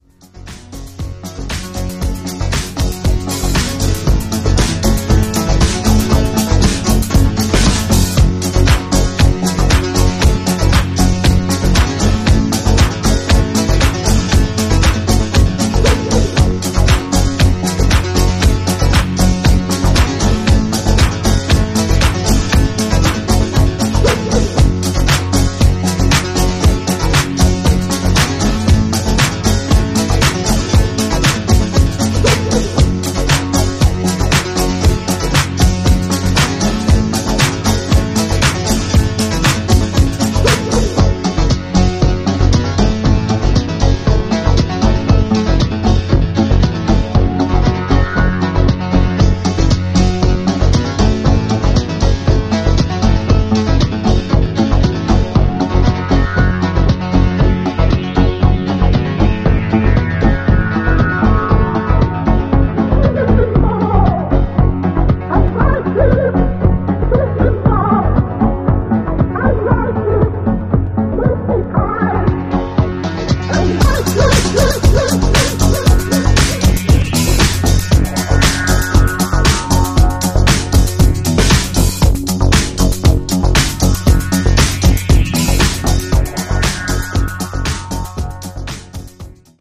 イタロ/アフロ/コズミック/バレアリック/ニューウェイヴ等の要素を持った